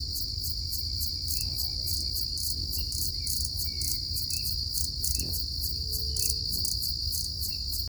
Gilded Sapphire (Hylocharis chrysura)
Detailed location: El Caraguatá
Condition: Wild
Certainty: Observed, Recorded vocal
Picaflor-Bronceado_1.mp3